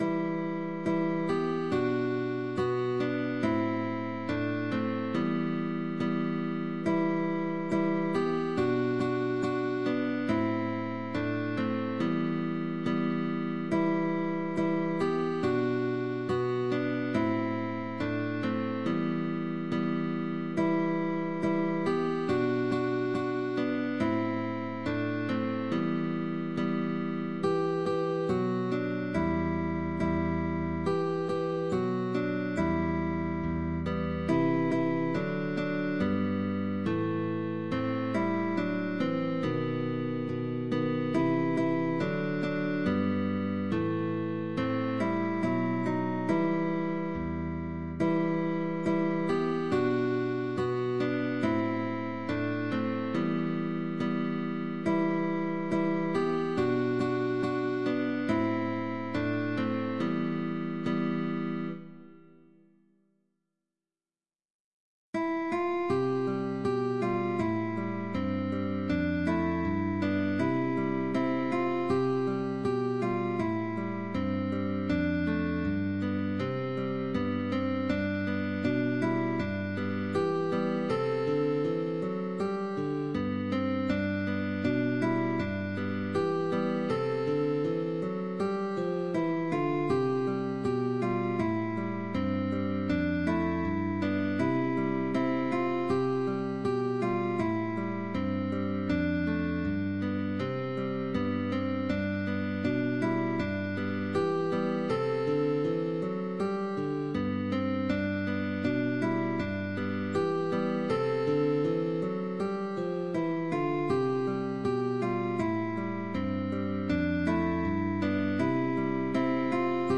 DÚO de GUITARRAS – Alumno y Profesor
Muy fácil, Pulsación «apoyando», Cambios de posición.